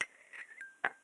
描述：这些声音来自于一个会话，我需要用茶具录制非常温和的碰撞声。设置是一个MXL3000话筒通过neve portico前置放大器。
Tag: 铮铮 碰杯 咖啡 浇注 茶杯